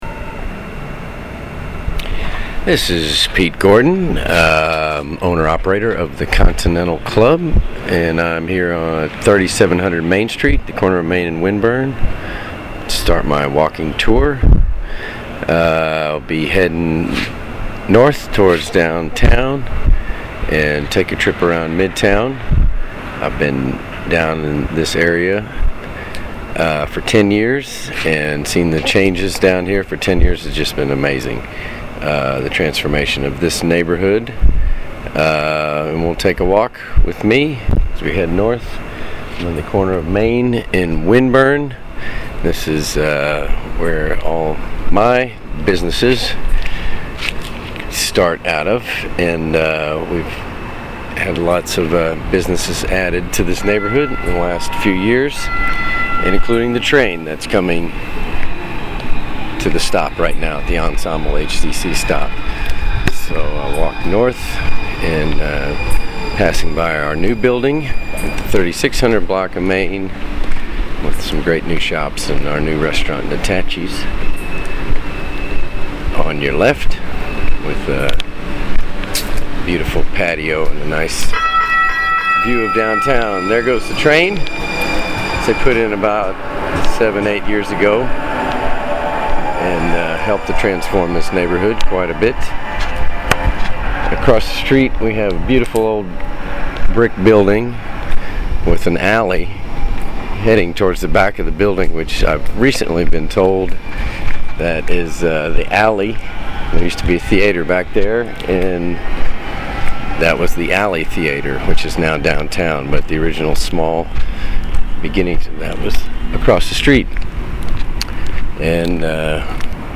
takes us on a looped walk around Midtown
Sink into a Southern pace